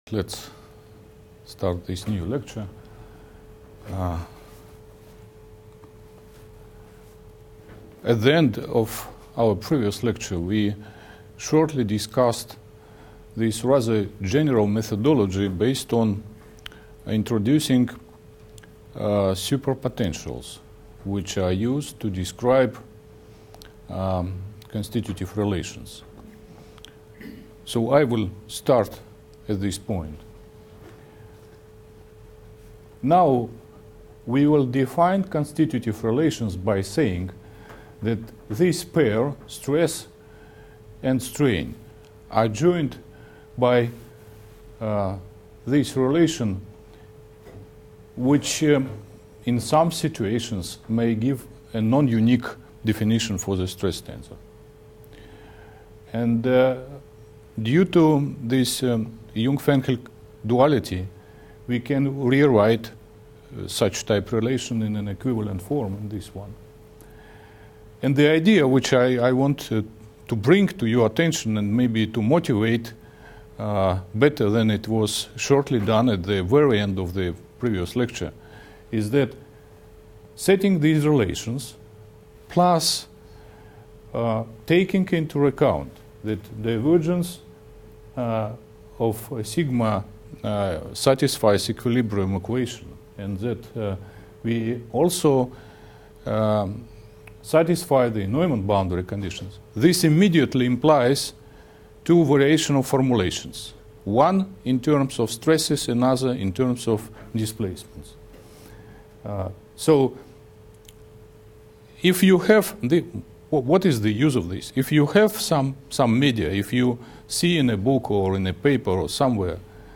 lecture series on mathematical theory of plasticity